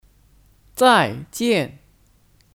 再见 Zàijiàn (Kata kerja): Sampai jumpa